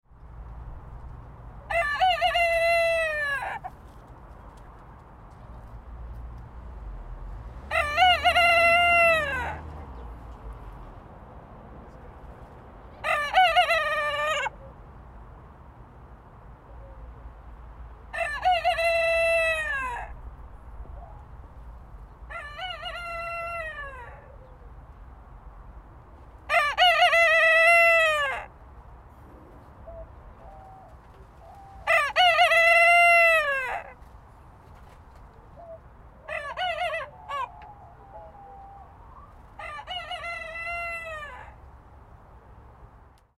دانلود آهنگ آواز خواندن خروس بدصدا و گوش خراش از افکت صوتی انسان و موجودات زنده
دانلود صدای آواز خواندن خروس بدصدا و گوش خراش از ساعد نیوز با لینک مستقیم و کیفیت بالا
جلوه های صوتی